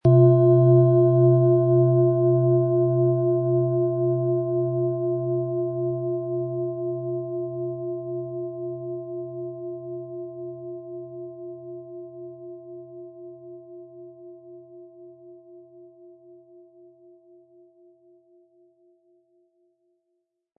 Von Hand getriebene tibetanische Planetenschale Wasser.
Der Schlegel lässt die Schale harmonisch und angenehm tönen.
MaterialBronze